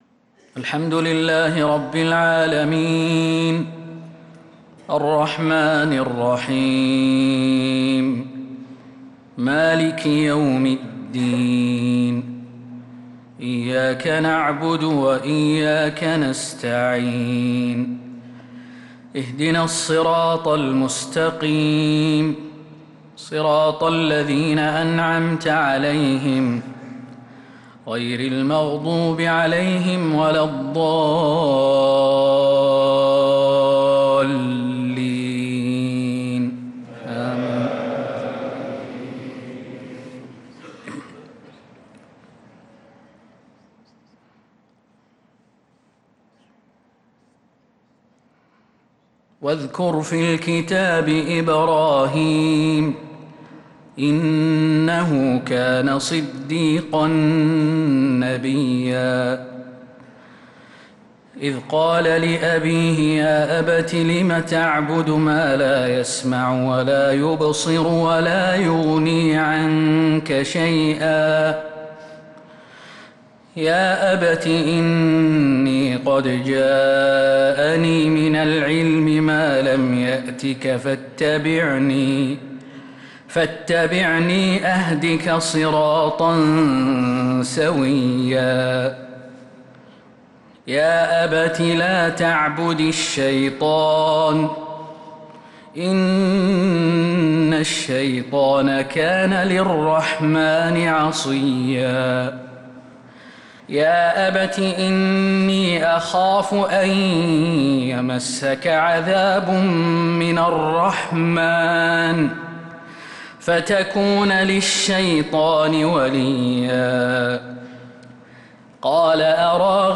عشاء الجمعة 3-7-1446هـ من سورة مريم 41-57 | isha prayer from Surat Maryam 3-1-2025 > 1446 🕌 > الفروض - تلاوات الحرمين